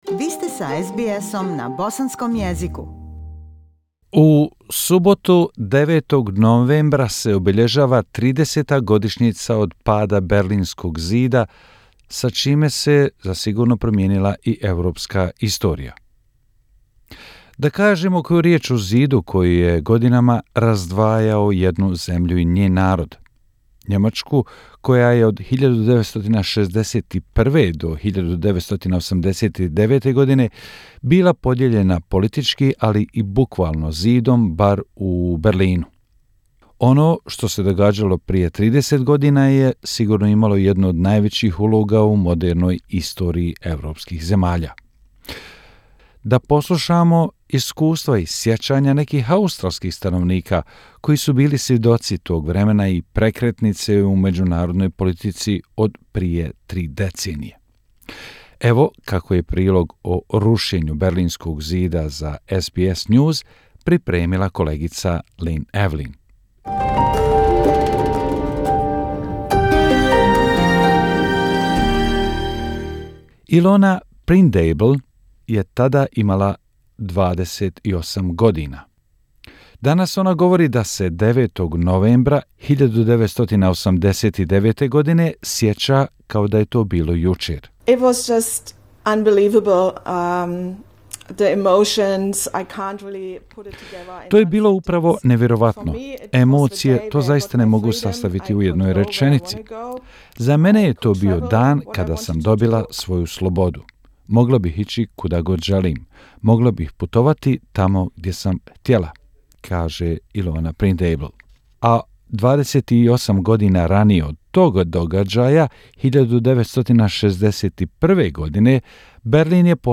This Saturday (Nov 9) marks the 30th anniversary of the day the Berlin Wall fell and European history changed forever. In this special report we meet Australian residents who have witnessed this pivotal piece of modern history... as they reflect back on the event 3 decades later.